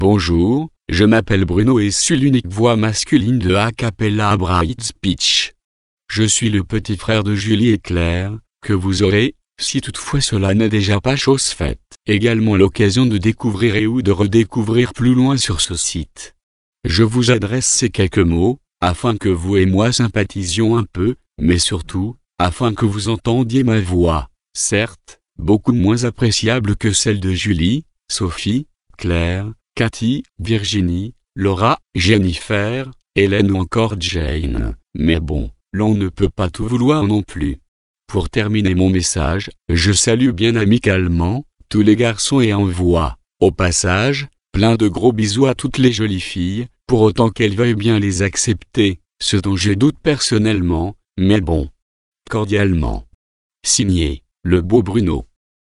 Texte de démonstration lu par Bruno, voix masculine française d'Acapela Infovox Desktop Pro
Écouter la démonstration de Bruno, voix masculine française d'Acapela Infovox Desktop Pro